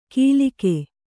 ♪ kīlike